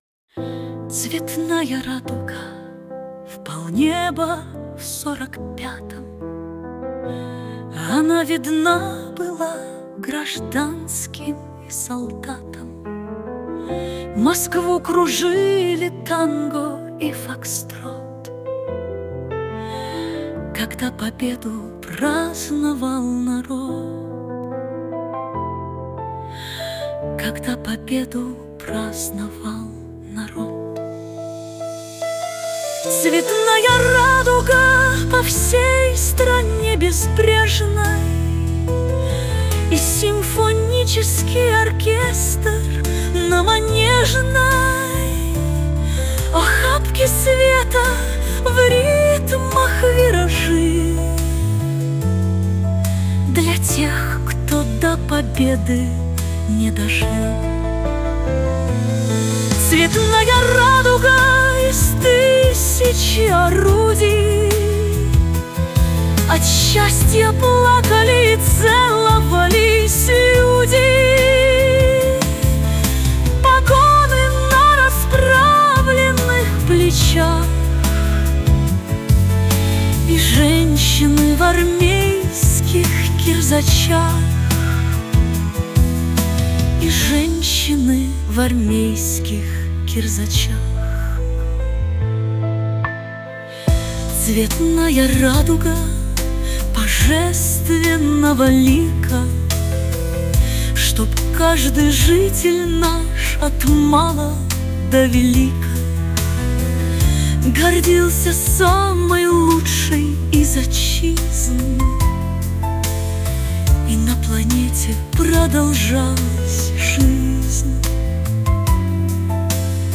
Многие из его стихов в настоящее время положены на музыку в жанре шансон различными исполнителями. Одна из последних песен — «Радуга Победы» — посвящена 80-летию Победы в Великой Отечественной войне 1941−1945 гг.
Так-то я ведь не музыкант, но с помощью искусственного интеллекта, ну, скажем, в соавторстве с ним, теперь можно делать и такие вещи.
Песня и вправду оказалась актуальной, как и «Спецоперация «Z», и даже в исполнении нейросети звучит вполне достойно.